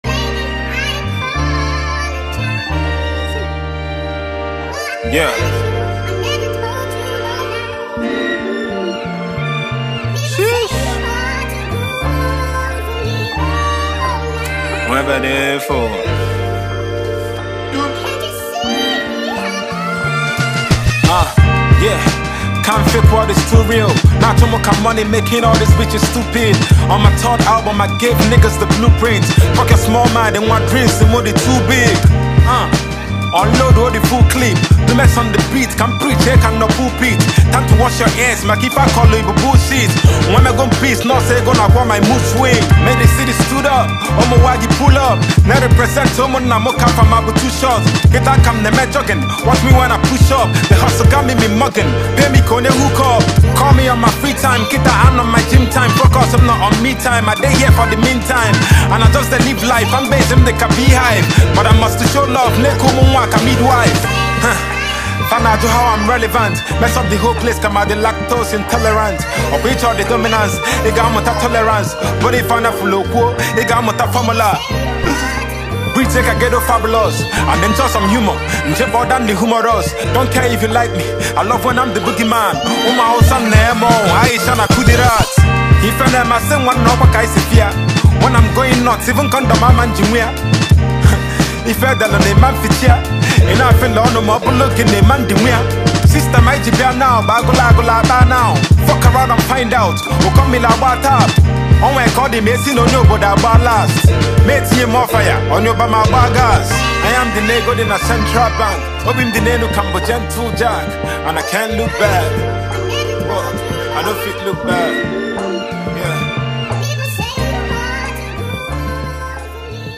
high vibe melody